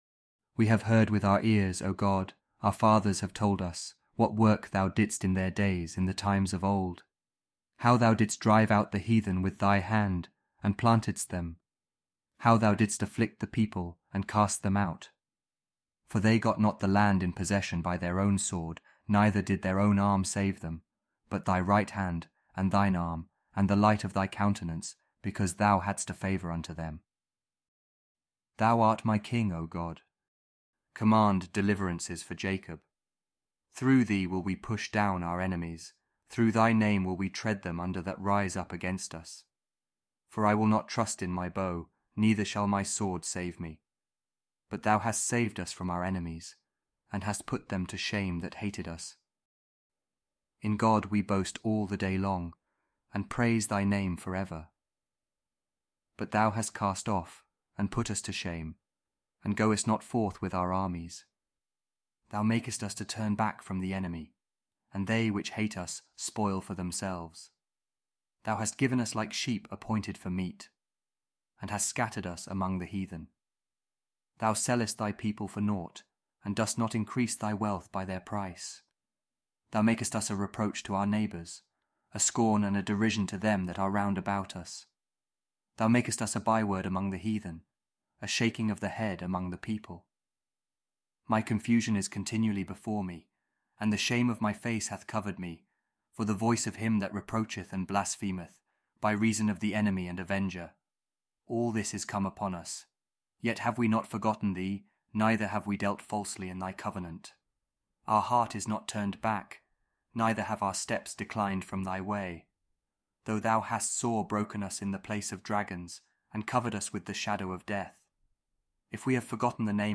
Psalm 44 | King James Audio Bible